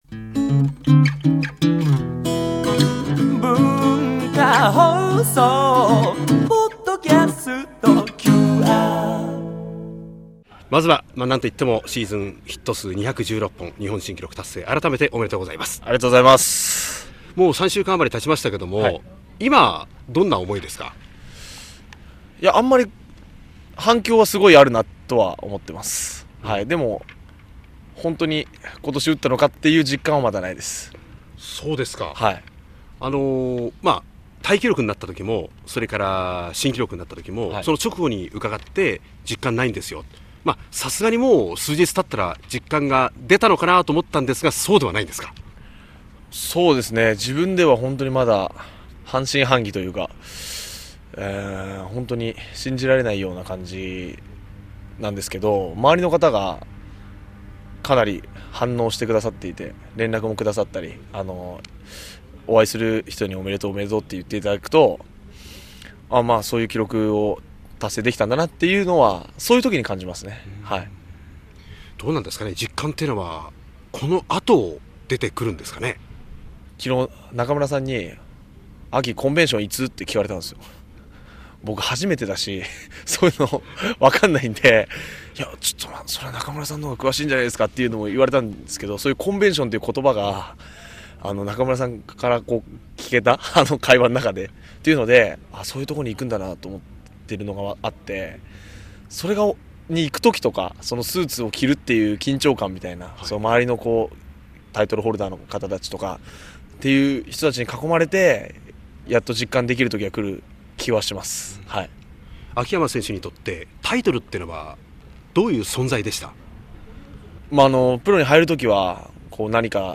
◆タイトルホルダーインタビュー～秋山翔吾～